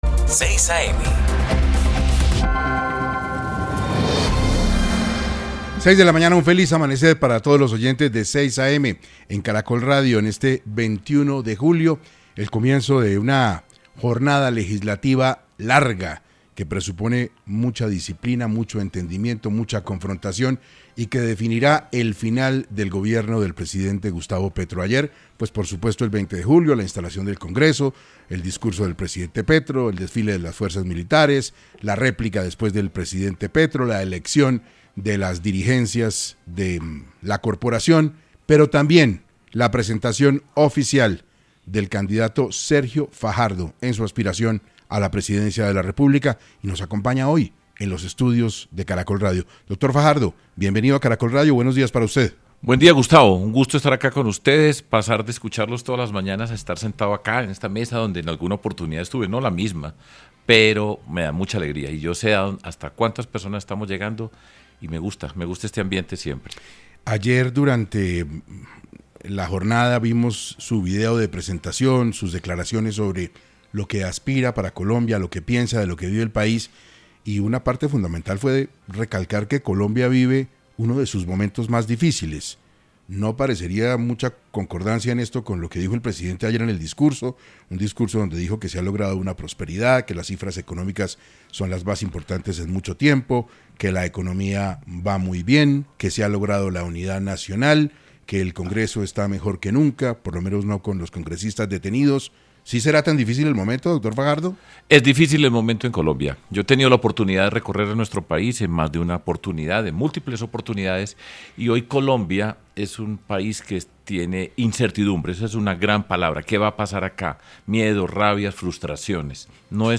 Fajardo entregó su primera entrevista radial a 6AM desde que oficializó su candidatura y explicó por qué cree que los colombianos lo escogerían como el próximo presidente.